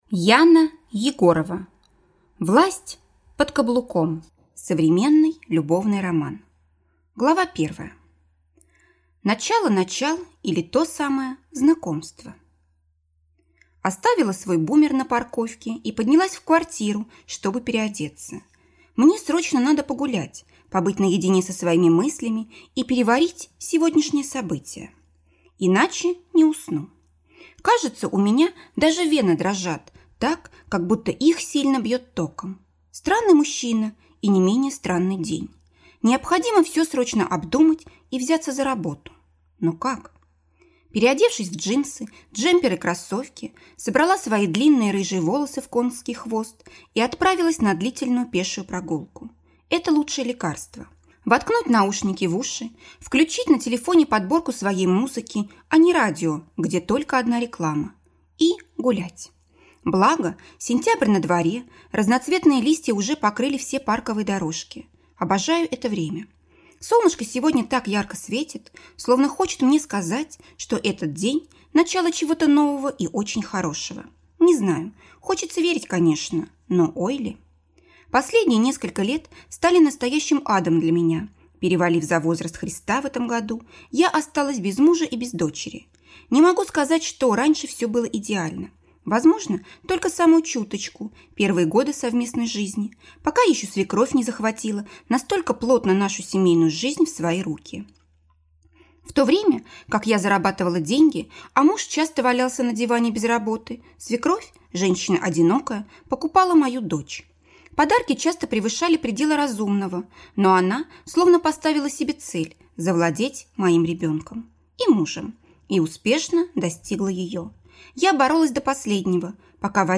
Аудиокнига Власть под каблуком | Библиотека аудиокниг